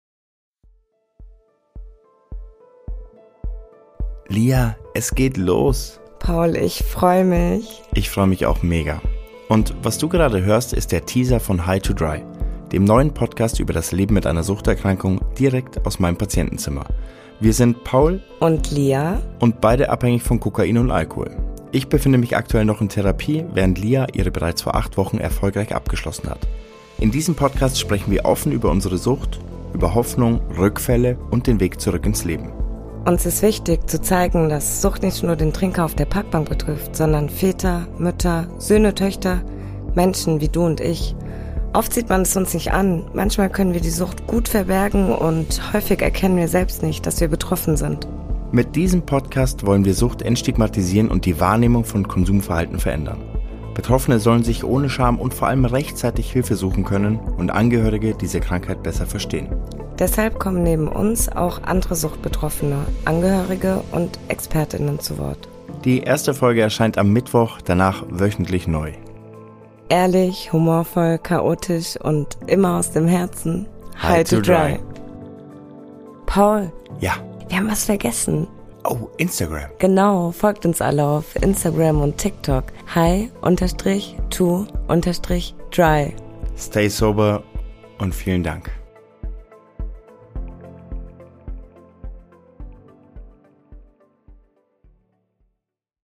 Erster deutscher Podcast Live aus der Suchtklinik